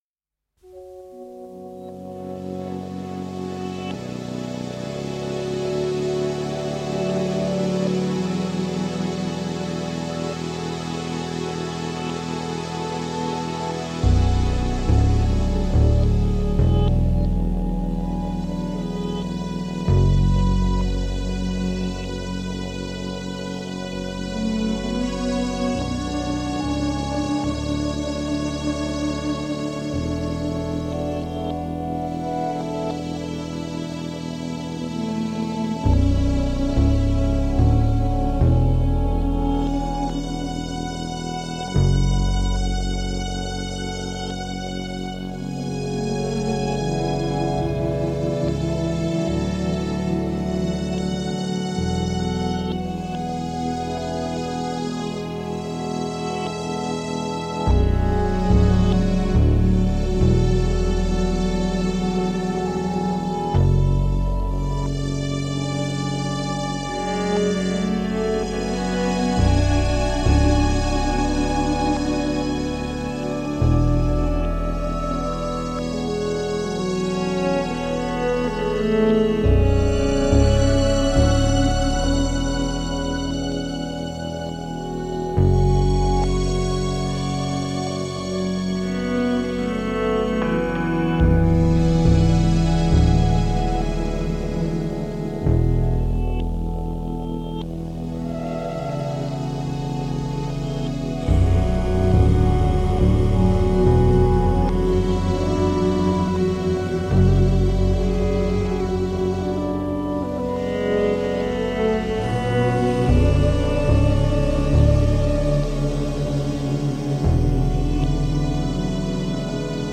موسیقی امبینت موسیقی تجربی